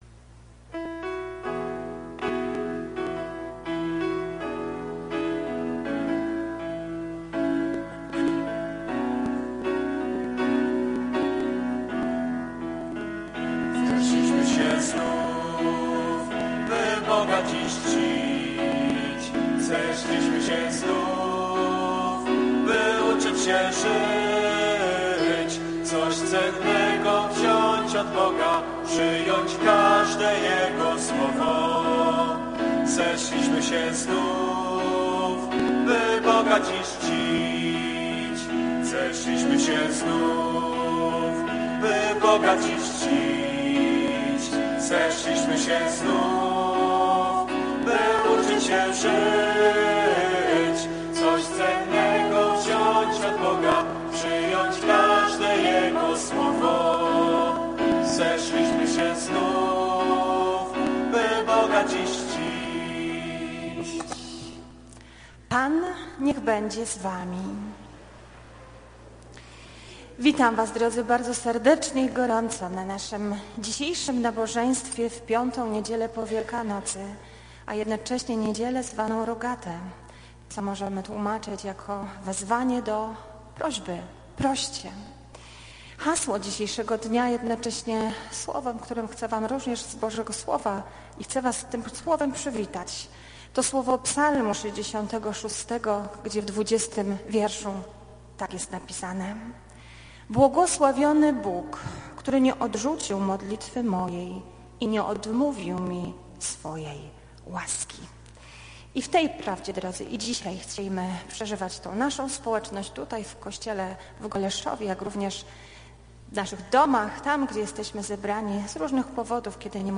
3 NIEDZIELA PO WIELKANOCY